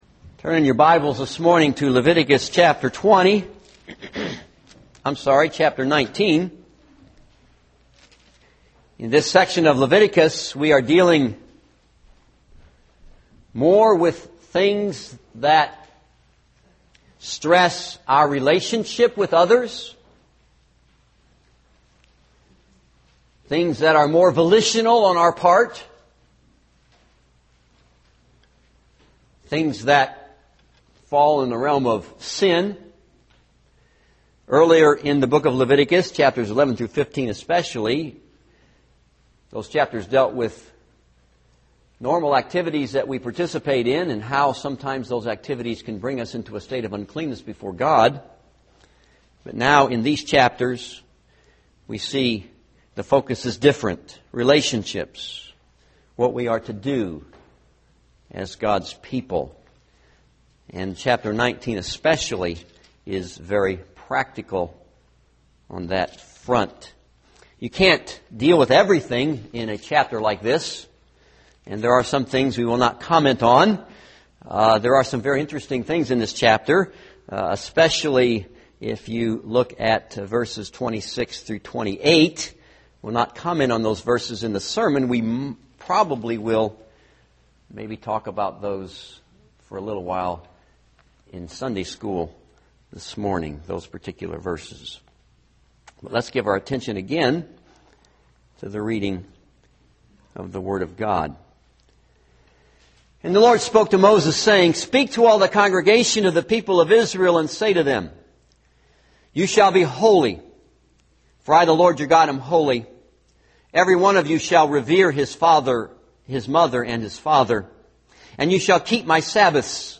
This is a sermon on Leviticus 19.